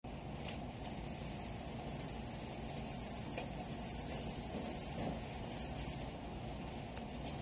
スズムシの　音色（ねいろ）を　聞（き）　きながら、
（↓ひだりの　▶を　おすと、　スズムシの　なきごえを　きくことが　できます）
リーン　リーン